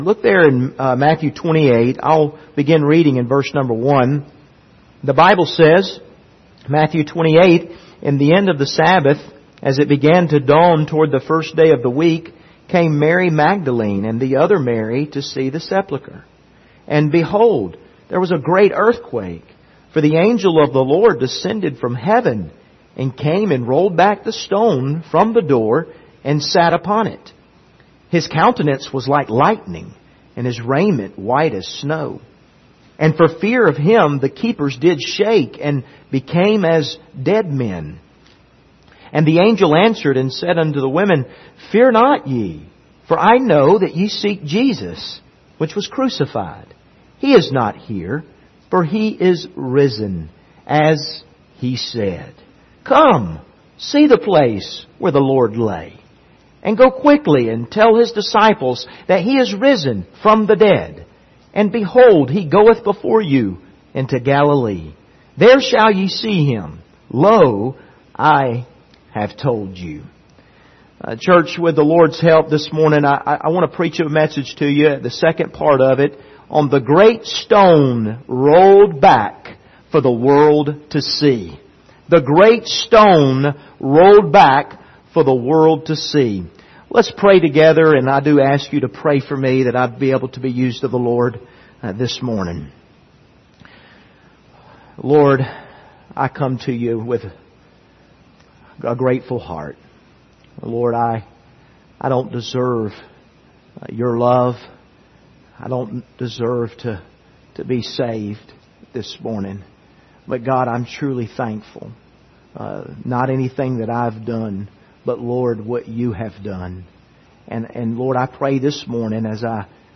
Service Type: Sunday Morning Topics: resurrection